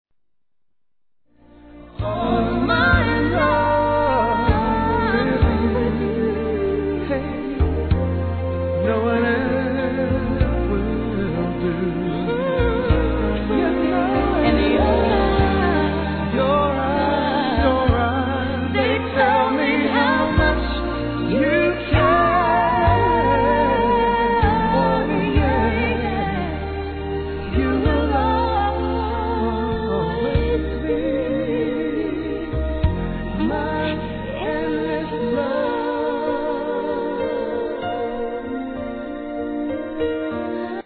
1. HIP HOP/R&B
スロウでムーディー、甘〜いセレクトで今回もバカ売れ確実！！